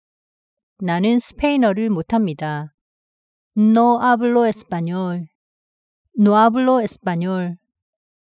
ㅣ노 아블로 에스빠뇰ㅣ